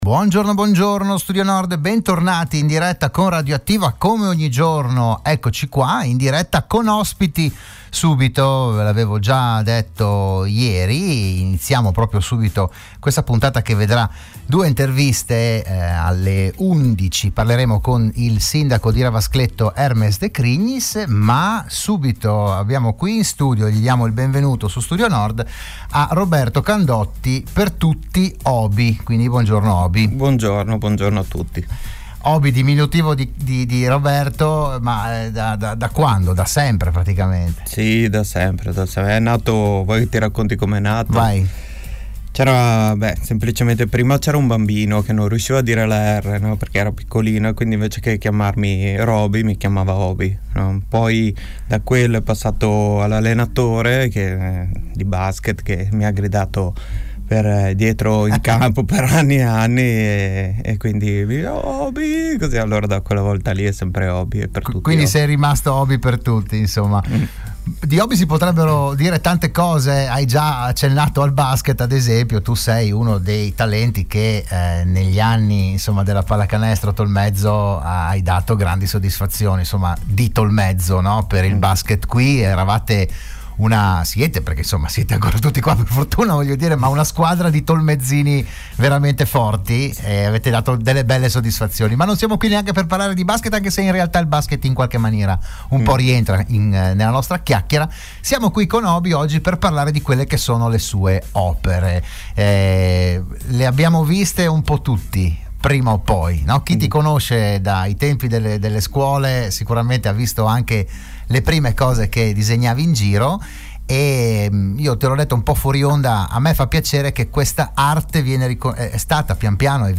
L'AUDIO e il VIDEO dell'intervento alla trasmissione "RadioAttiva"